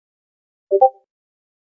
NewMessageTone.mp3